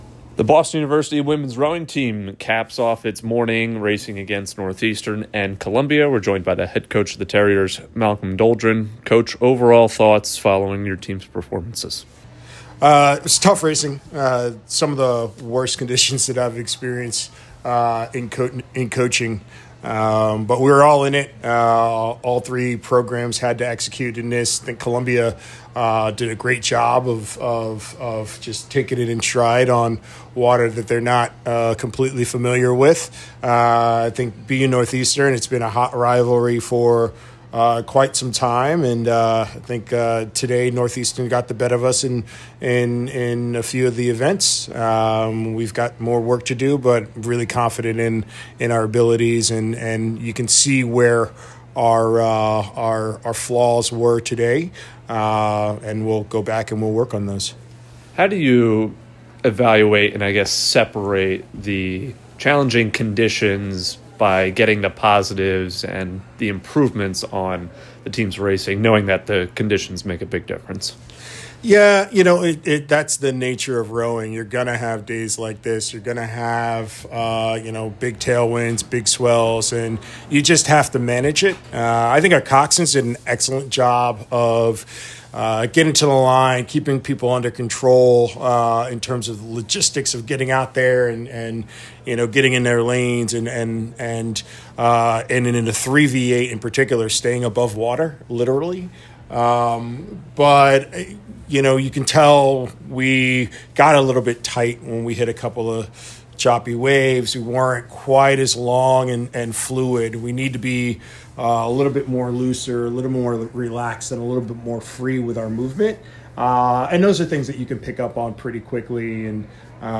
Women's Rowing / Columbia and Northeastern Postrace Interview